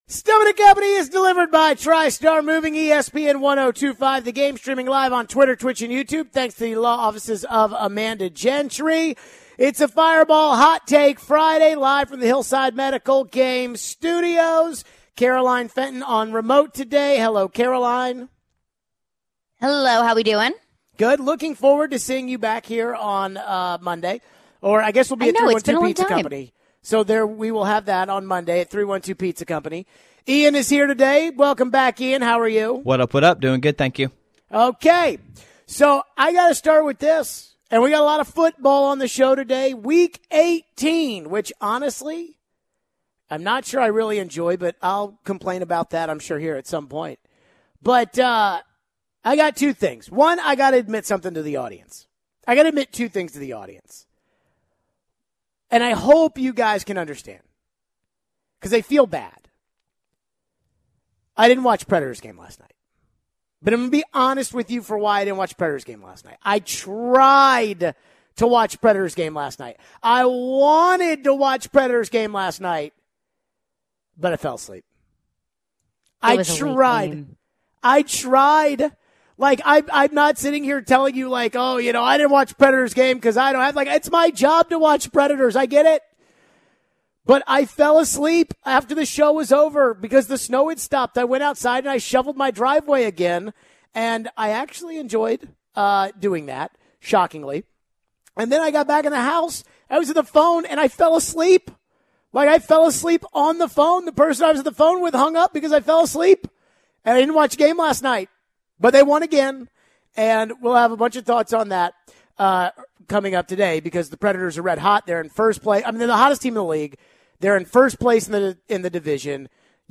Are the Titans the favorite in the AFC if they win this week? We take your phones. Plus the Preds stay hot with a win over the Kings.